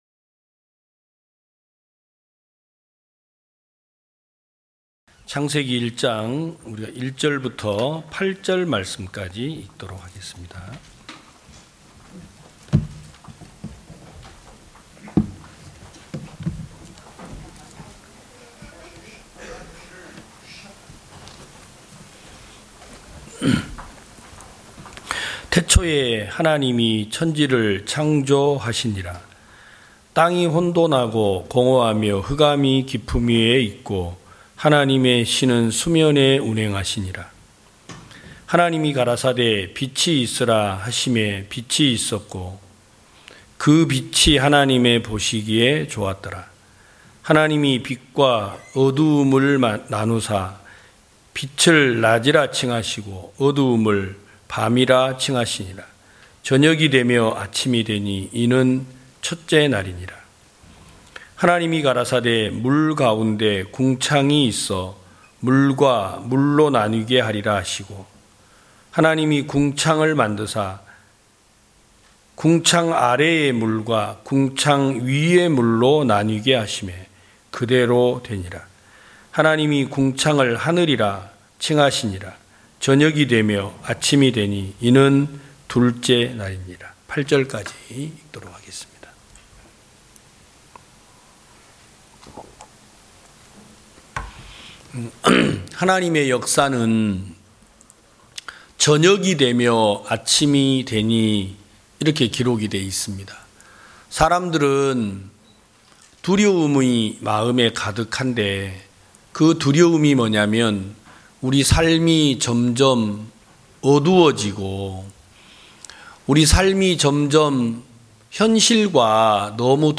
2022년 10월 23일 기쁜소식부산대연교회 주일오전예배
성도들이 모두 교회에 모여 말씀을 듣는 주일 예배의 설교는, 한 주간 우리 마음을 채웠던 생각을 내려두고 하나님의 말씀으로 가득 채우는 시간입니다.